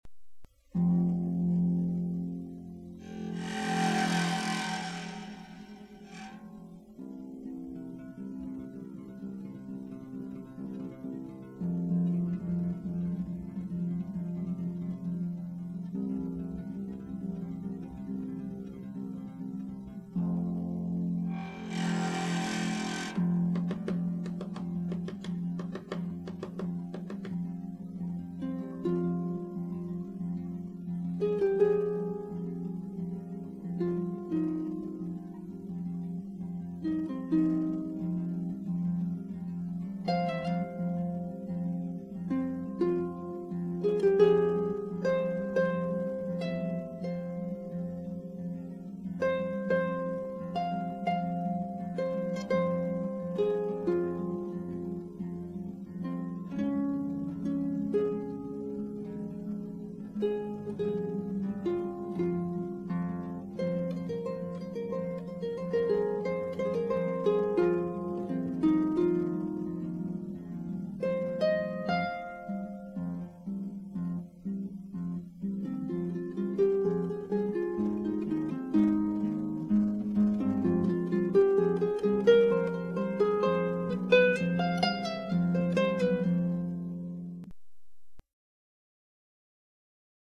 Pedal Harp Solo